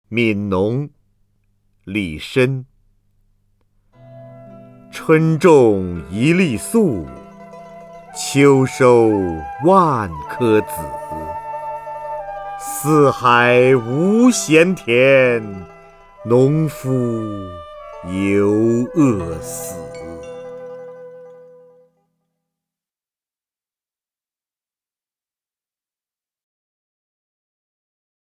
瞿弦和朗诵：《悯农·春种一粒粟》(（唐）李绅) （唐）李绅 名家朗诵欣赏瞿弦和 语文PLUS